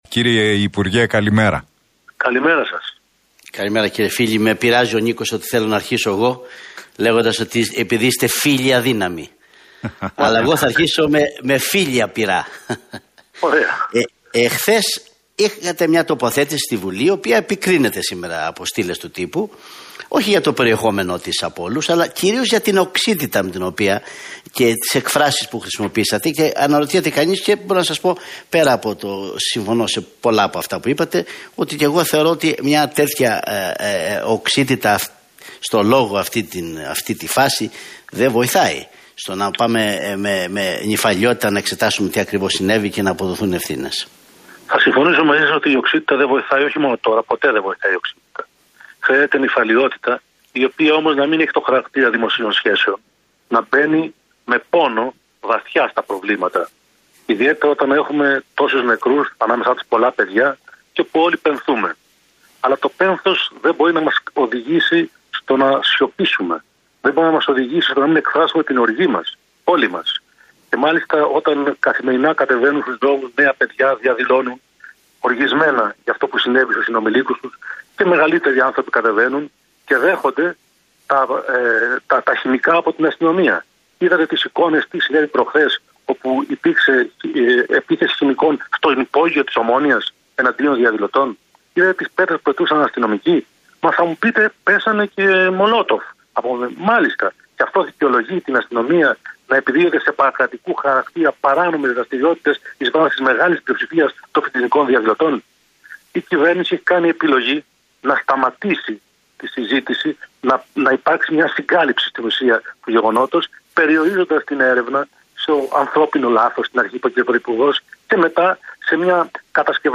Ο τομεάρχης Παιδείας και βουλευτής του ΣΥΡΙΖΑ, Νίκος Φίλης, μιλώντας στον Realfm 97,8